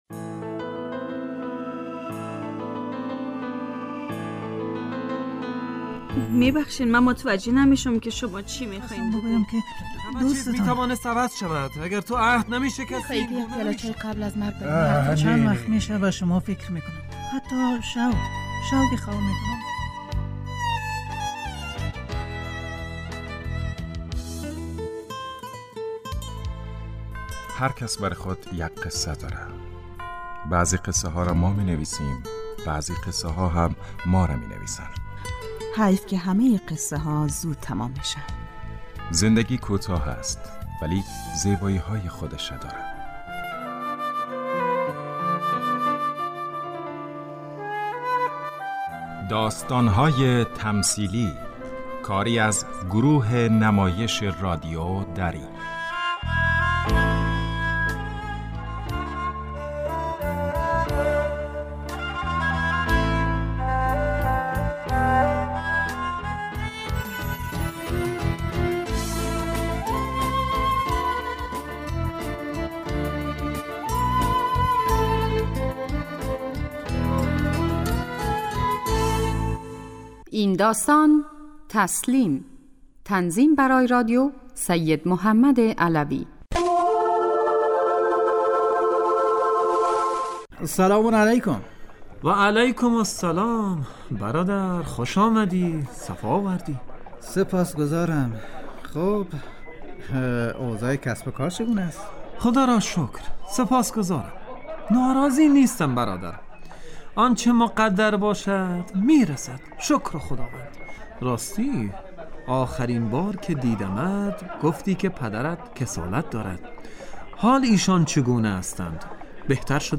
داستان تمثیلی / تسلیم